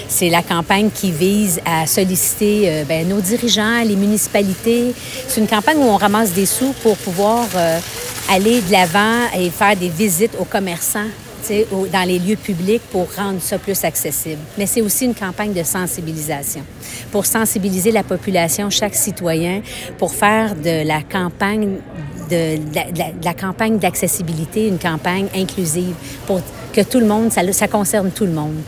En entrevue avec le Service de nouvelles de M105, explique l’objectif de la campagne.